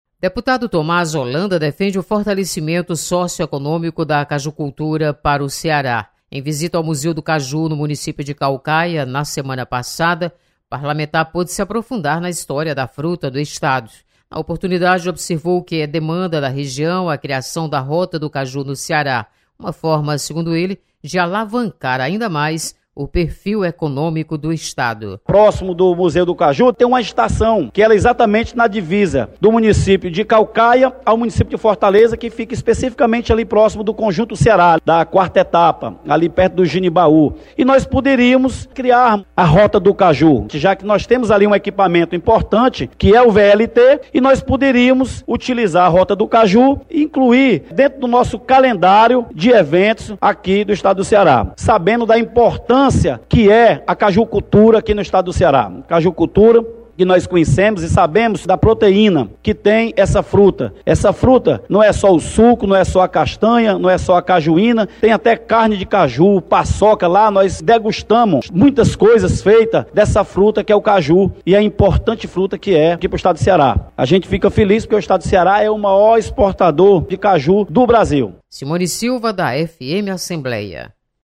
Deputado Thomaz Holanda defende fortalecimento da cajucultura. Repórter